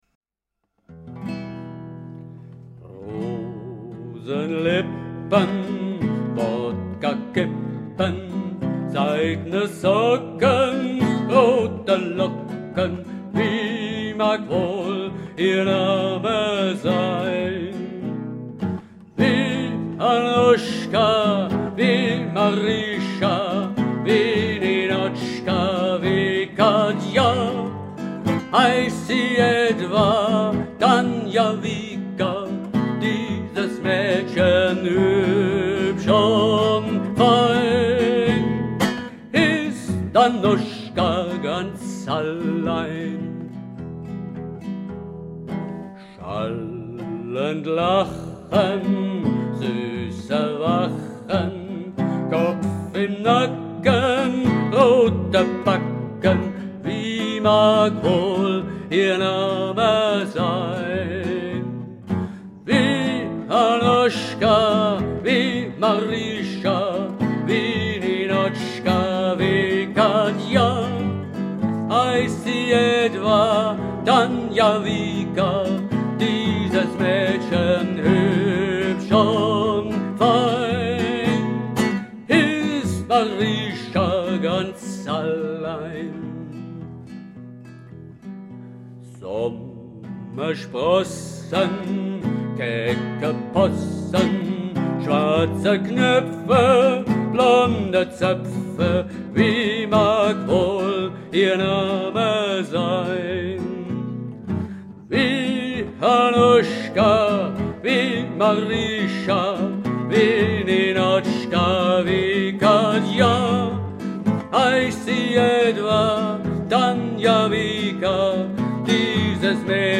(Gruppenfassung)
Die Hörprobe stammt von einer Studioaufnahme aus dem Jahr 2012.